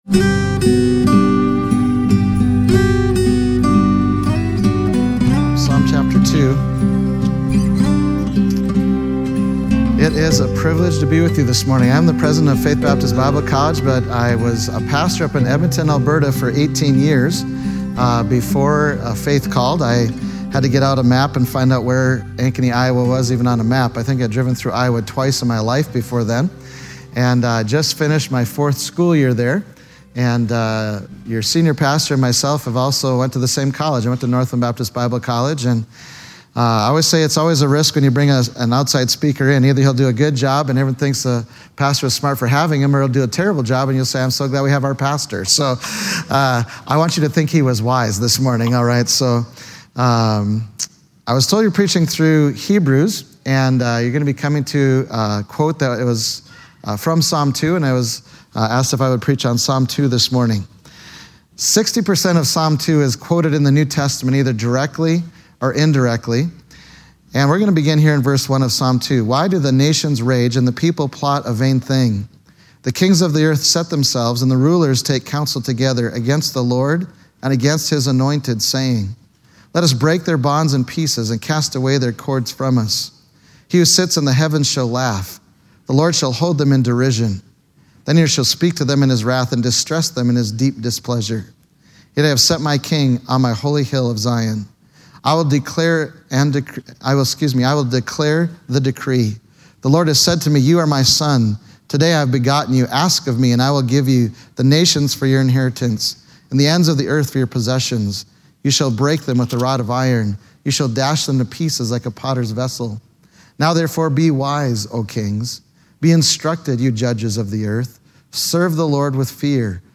Psalm 2 Service Type: Sunday Morning Worship « Romans 10 Analysis